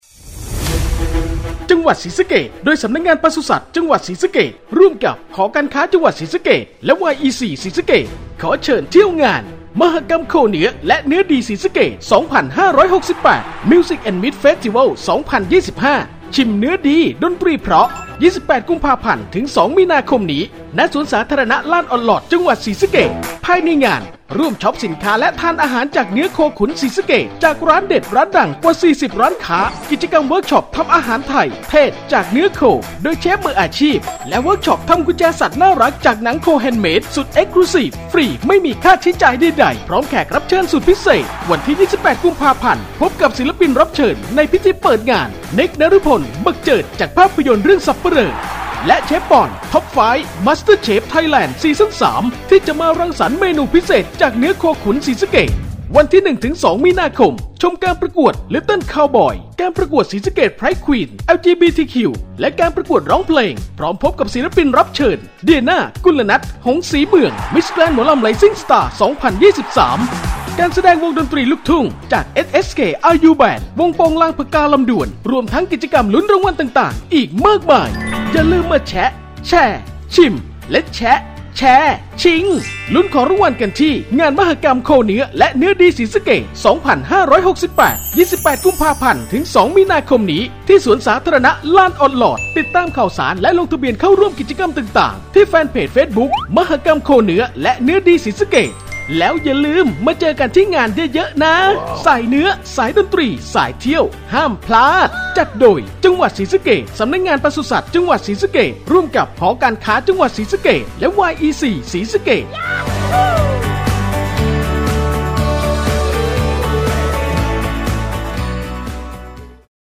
สปอตงานมหกรรมโคเนื้อและเนื้อดีศรีสะเกษ 2568